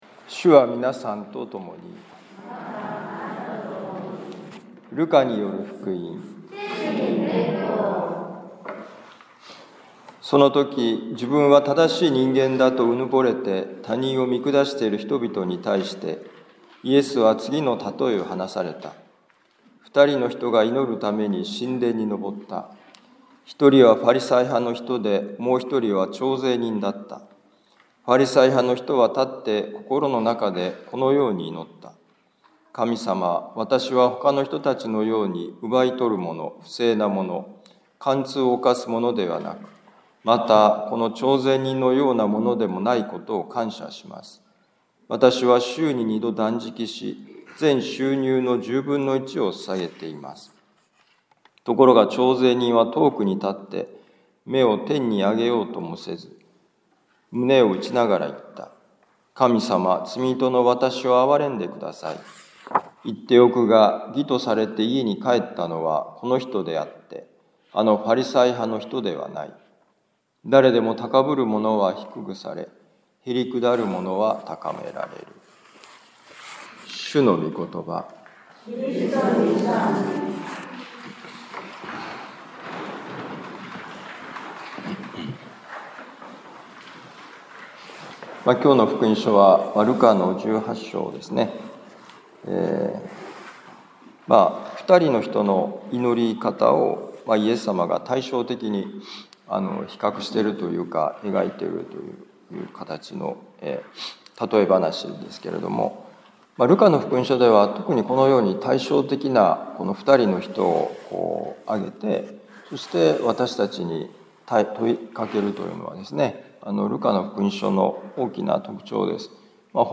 ルカ福音書18章9-14節「本当の祈り」2025年10月26日年間第30主日ミサ防府カトリック教会